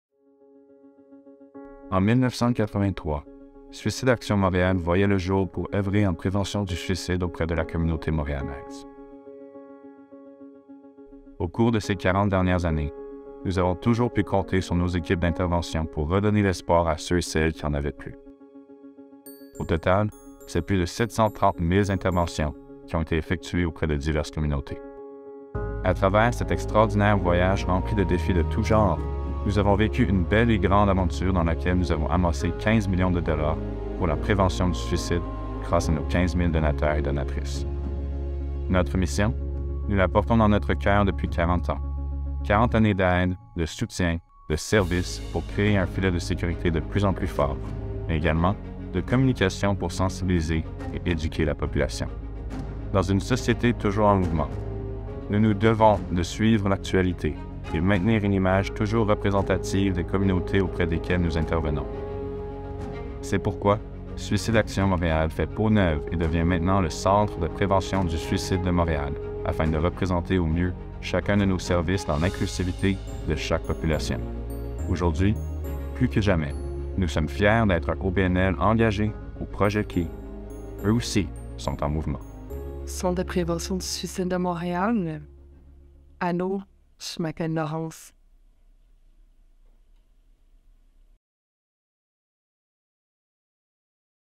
Narration 2 - FR